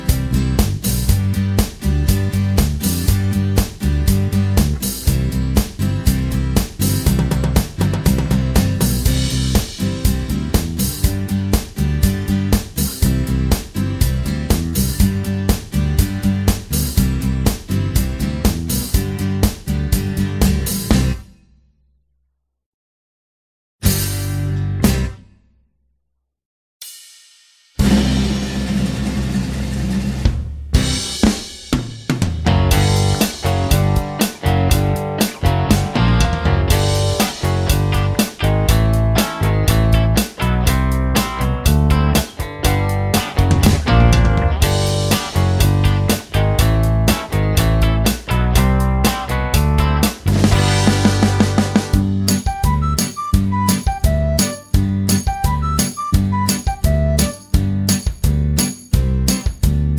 no Backing Vocals Comedy/Novelty 3:58 Buy £1.50